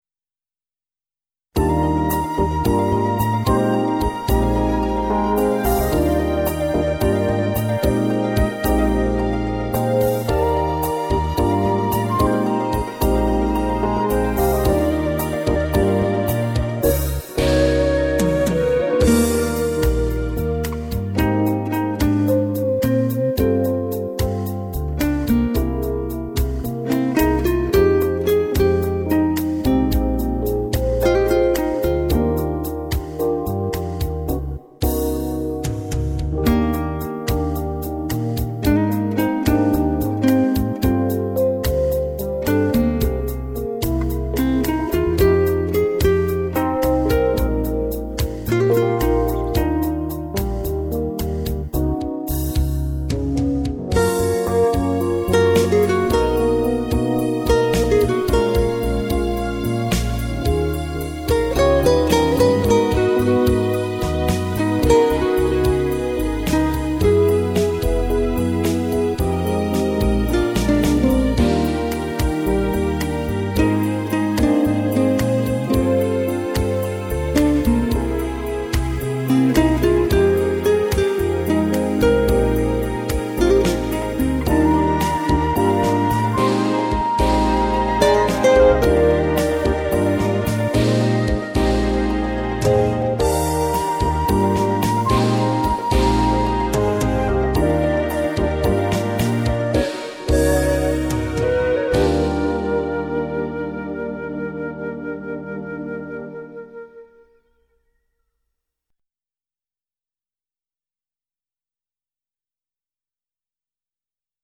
Light,Relax(1789K)